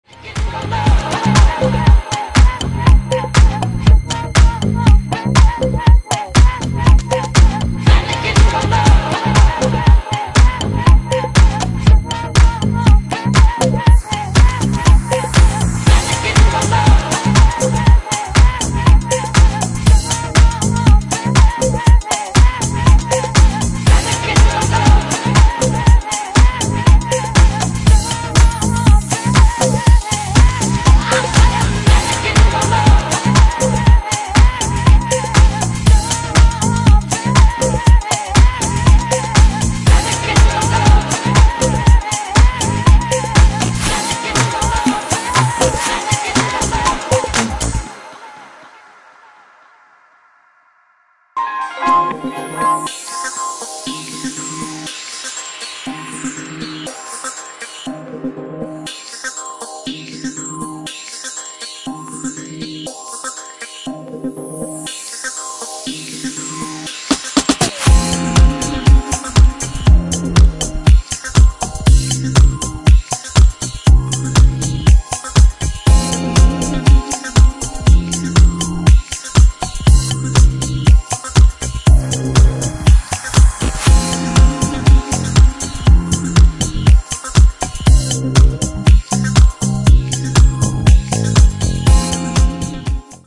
ブギーなディスコ・ハウスで盛り上がり間違いありません！！
ジャンル(スタイル) DISCO HOUSE / RE-EDIT